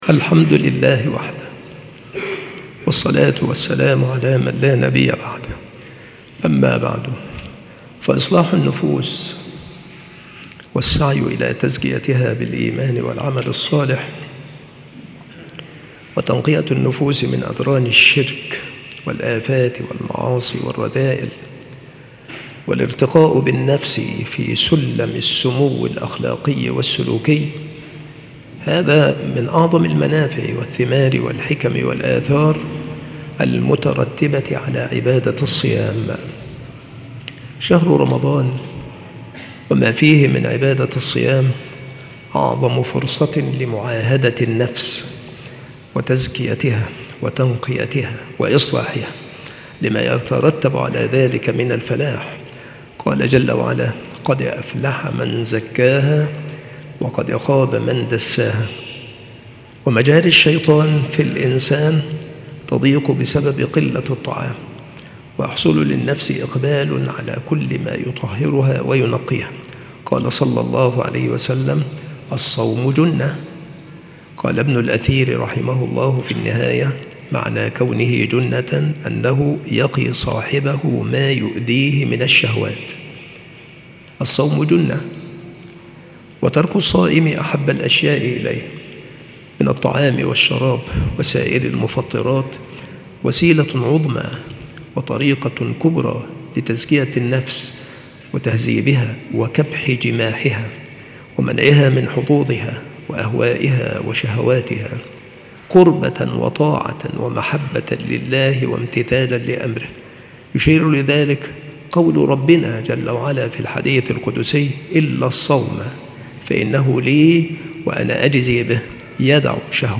السلسلة مواعظ وتذكير
مكان إلقاء هذه المحاضرة بالمسجد الشرقي - سبك الأحد - أشمون - محافظة المنوفية - مصر